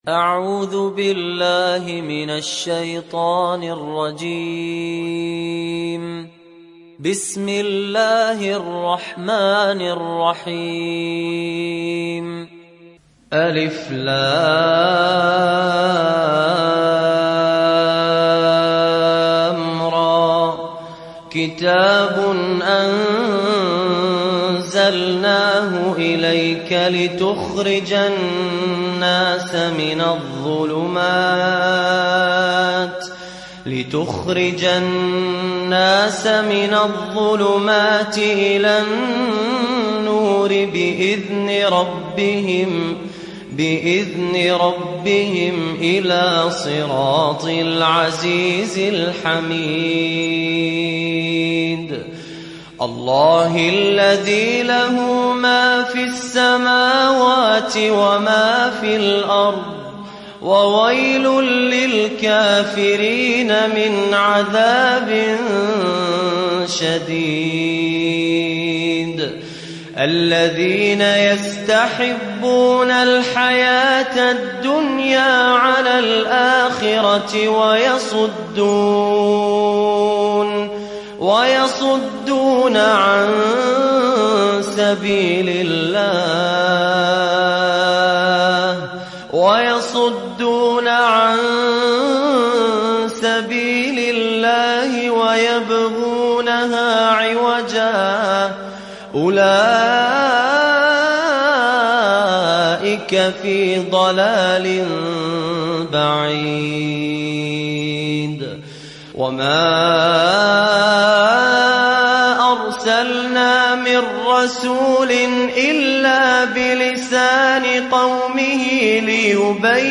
Sourate Ibrahim Télécharger mp3 Fahad Alkandari Riwayat Hafs an Assim, Téléchargez le Coran et écoutez les liens directs complets mp3